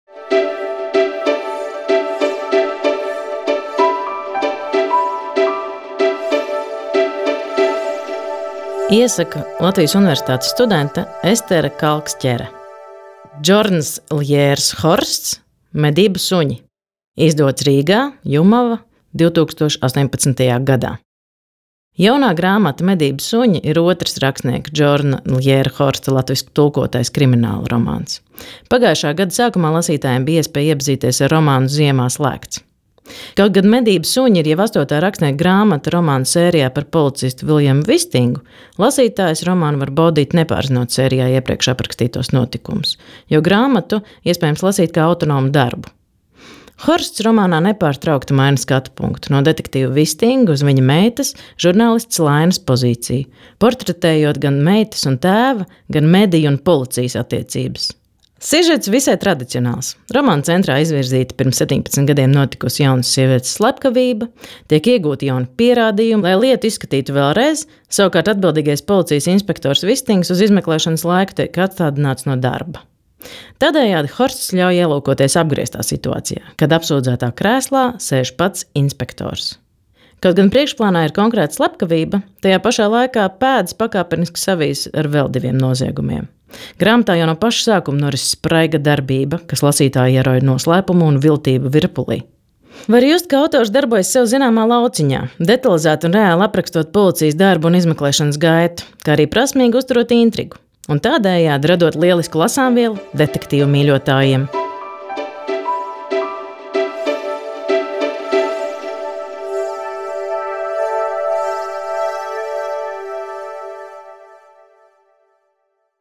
Latvijas Nacionālās bibliotēkas audio studijas ieraksti (Kolekcija)